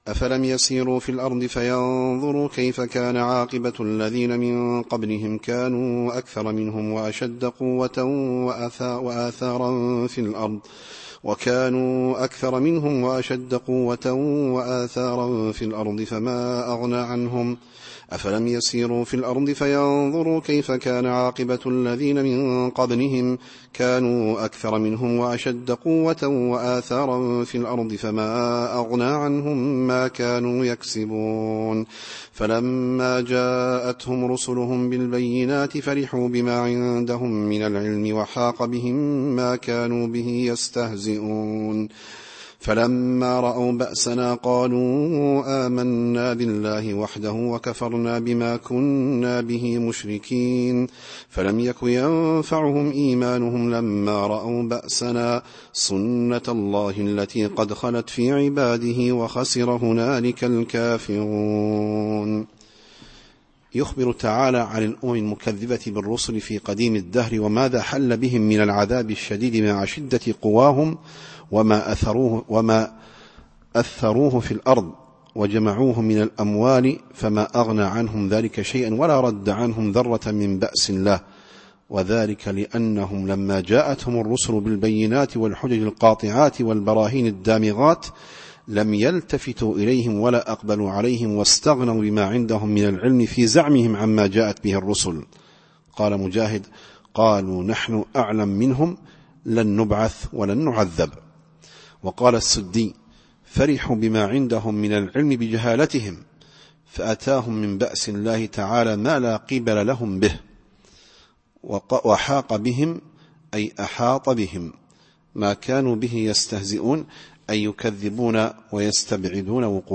التفسير الصوتي [غافر / 82]